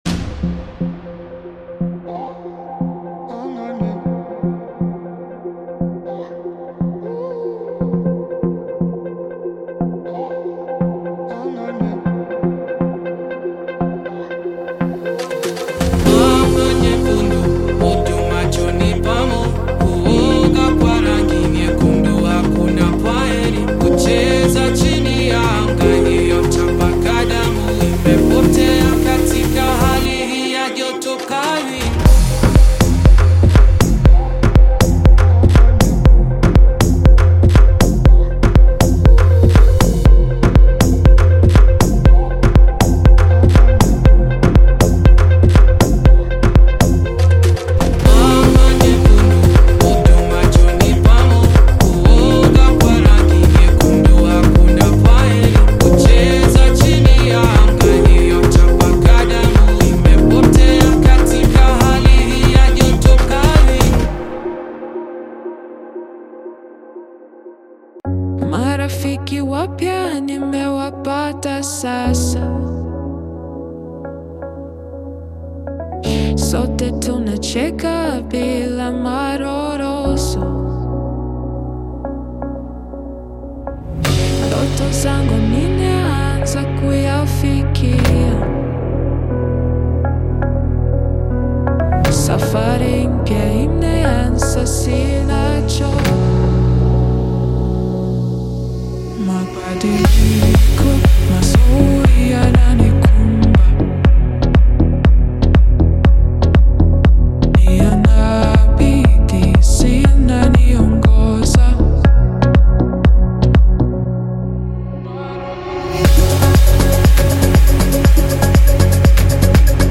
2025-12-31 Afro House · Tribal House 151 推广
该音色包包含催眠般的打击乐、氛围合成器以及优美的嗓音，完美捕捉了该音乐类型的温暖与精神。
音色丰富、动感十足且层次分明，无论是地下俱乐部氛围、音乐节热门金曲还是内省式作品，都能轻松驾驭。